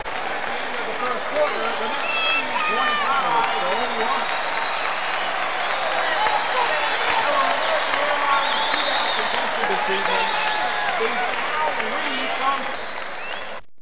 Descarga de Sonidos mp3 Gratis: evento deportivo.